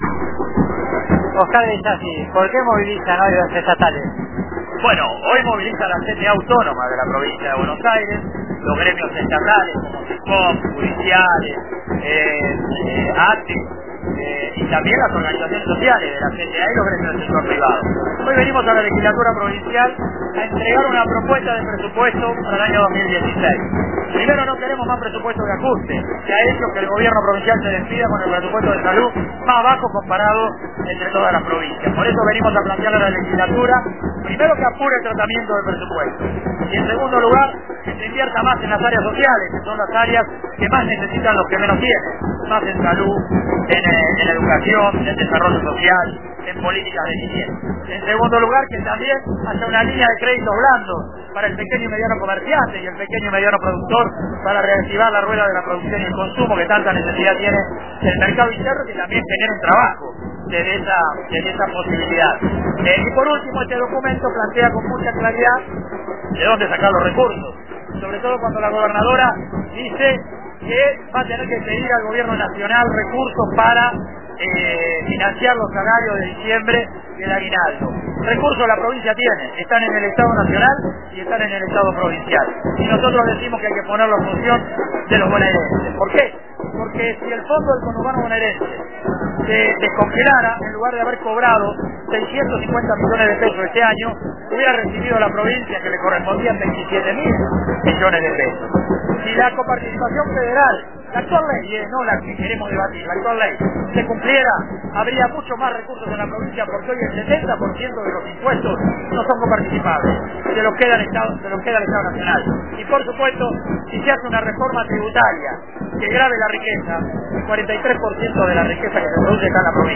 Movilización de ATE en la Legislatura Provincial: